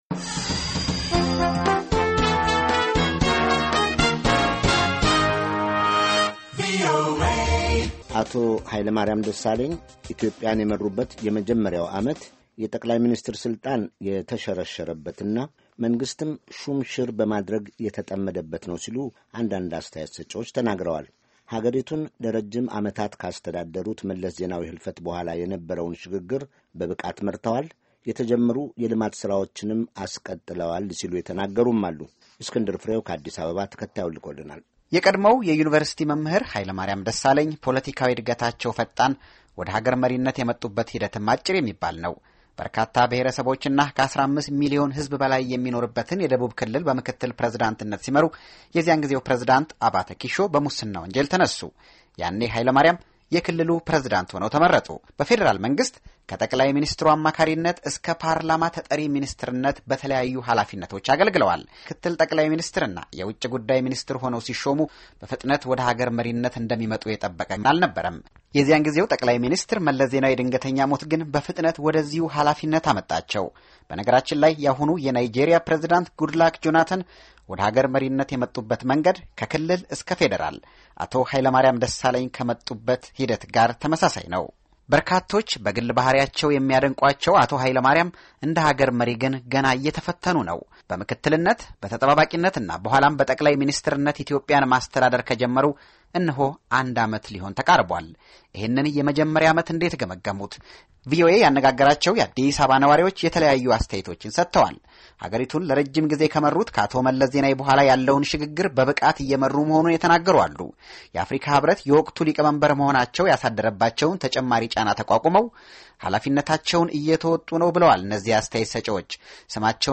Hailemariam Desalegn, PM, Ethiopia, vox-pop